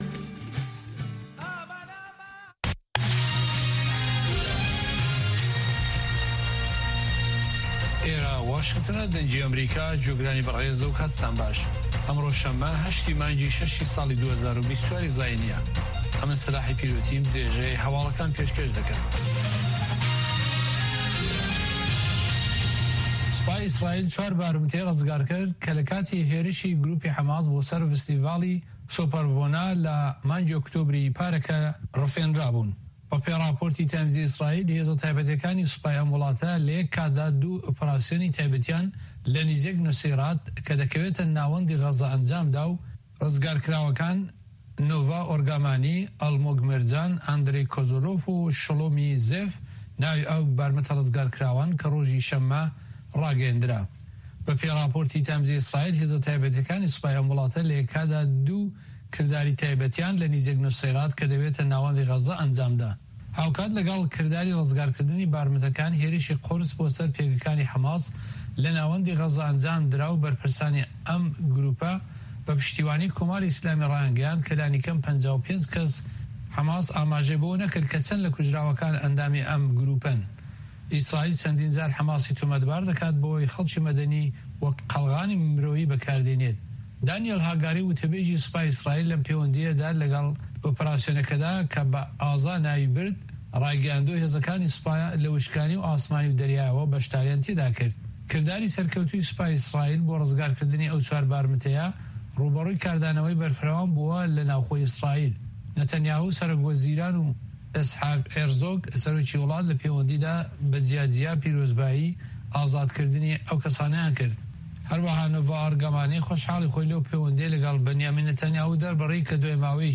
هەواڵەکانی 3 ی پاش نیوەڕۆ